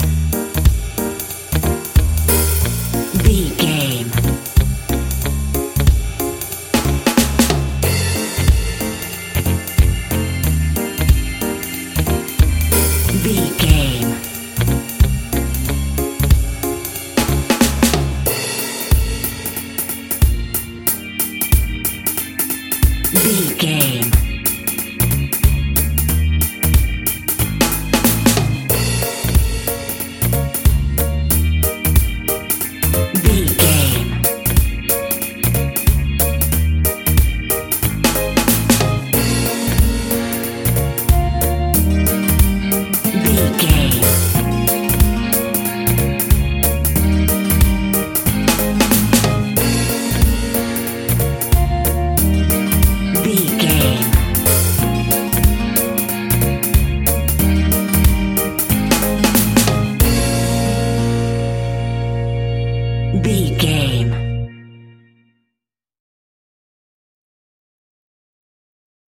A fast and speedy piece of Reggae music, uptempo and upbeat!
Uplifting
Aeolian/Minor
B♭
laid back
off beat
drums
skank guitar
hammond organ
percussion
horns